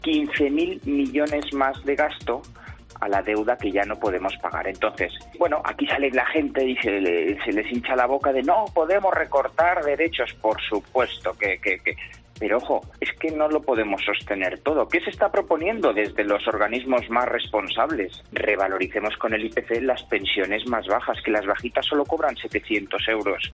Economista, sobre el incremento de las pensiones: “15000 millones de gasto a la deuda que ya no podemos pagar"